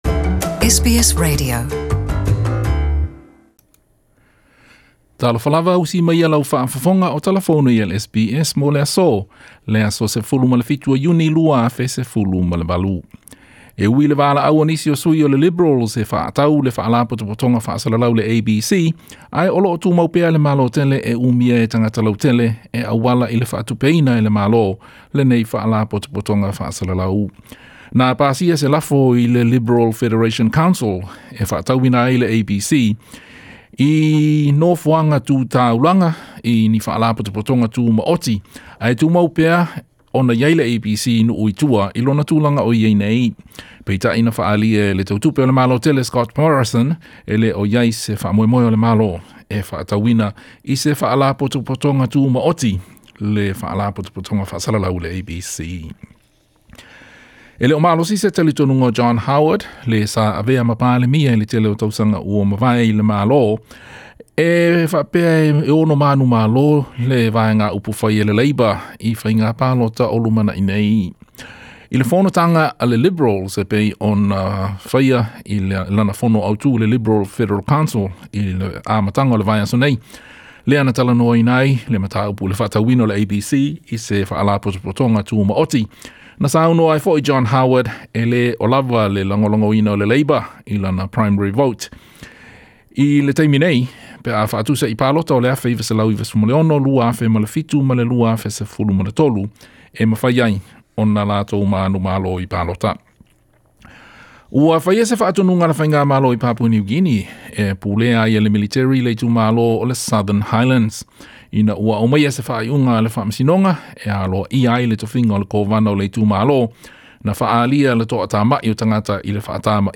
Talafou o Ausetalia ma va o Malo.